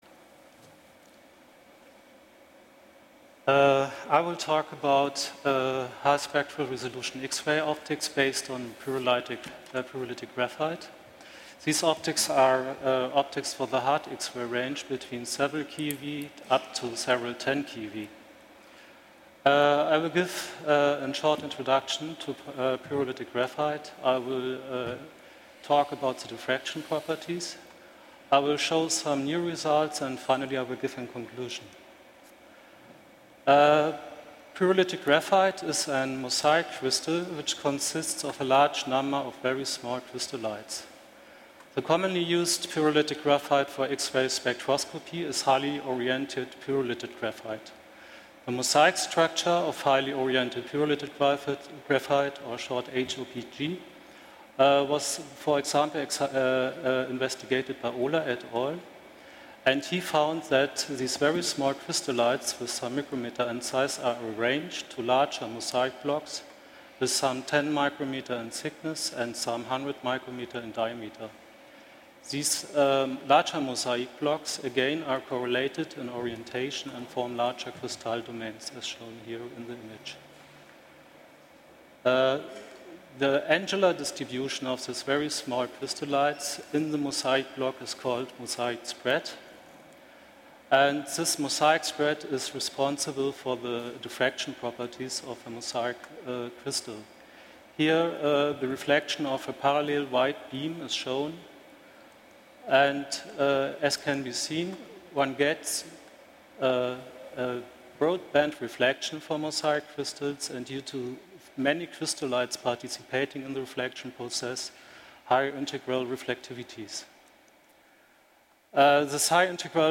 In the present work we will present new results concerning the energy resolution, integral reflectivity and application of thin bent Graphite films. Slides Talk